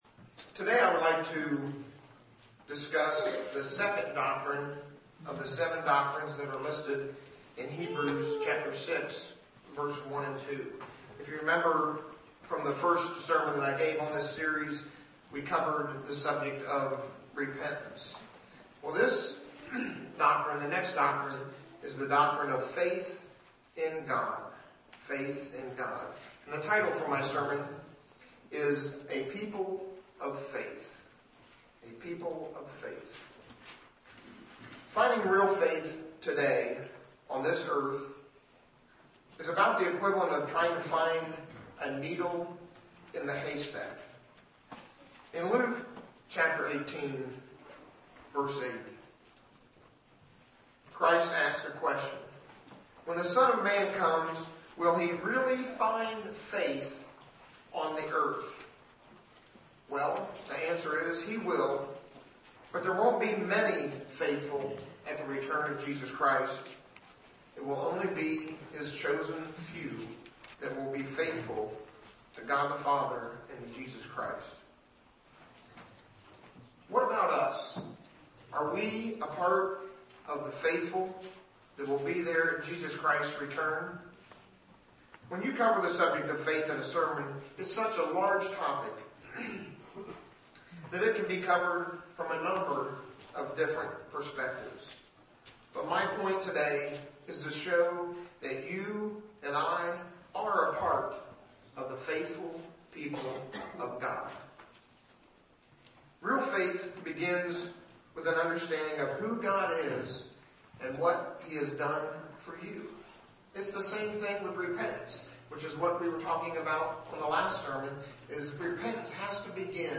This sermon was given at the Jekyll Island, Georgia 2016 Feast site.
UCG Sermon Studying the bible?